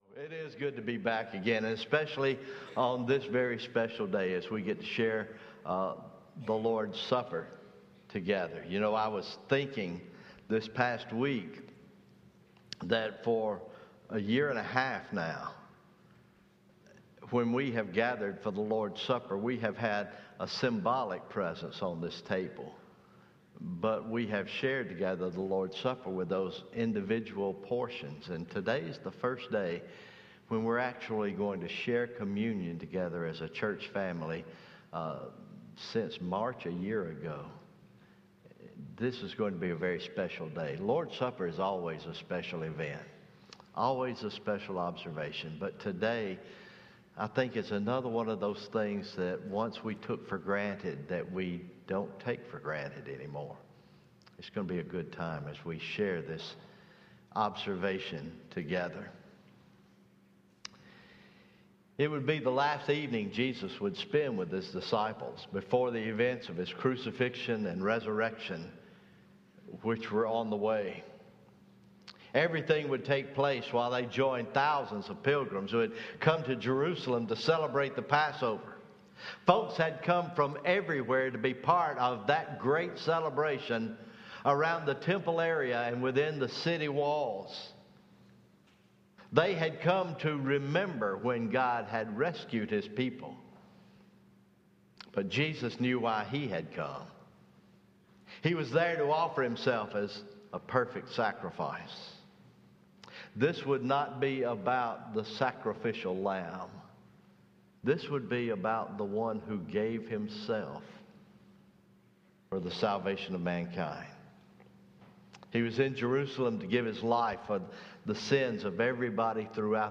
Morning Worship – Communion